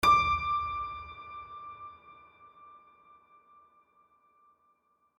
HardPiano